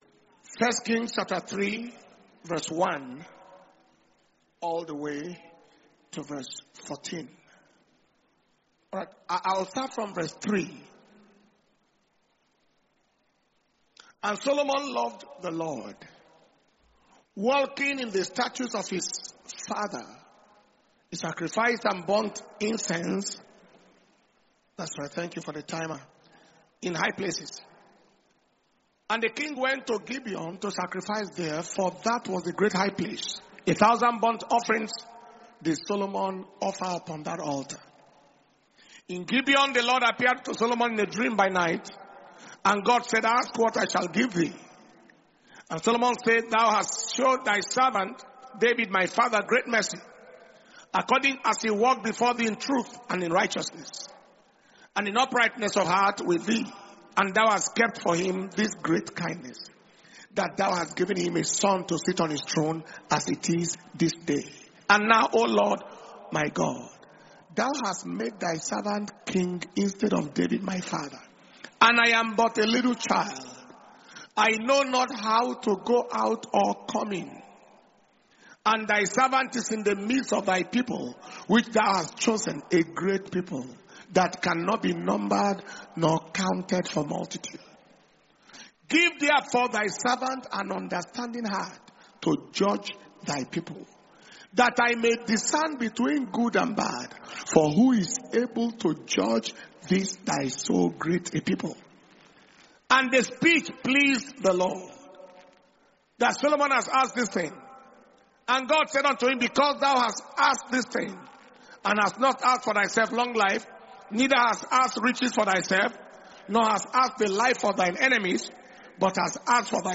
Dunamis International Ministers’ Flaming Fire Conference (IMFFC 2025) August 2025 – Day 4 Night Vigil